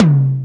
DX Tom 02.wav